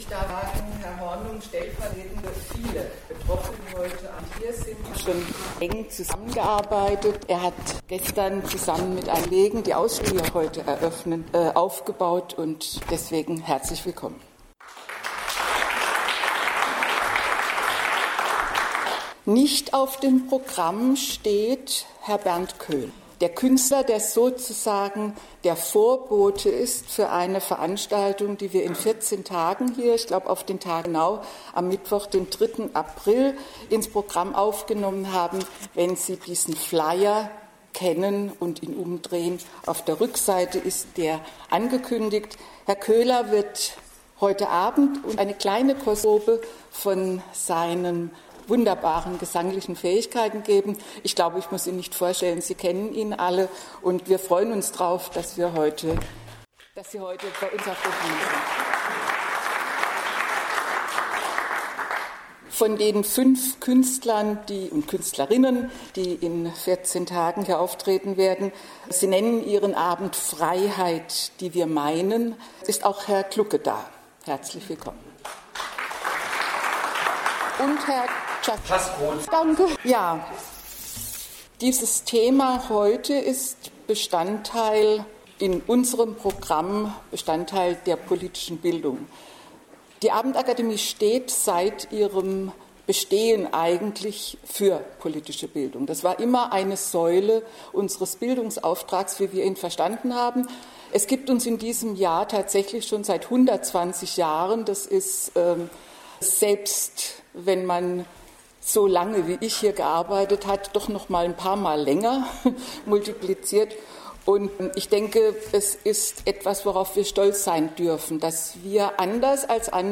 20.03.2019 Mannheim: Ausstellungseröffnung in der Abendakademie
Nach Grußworten (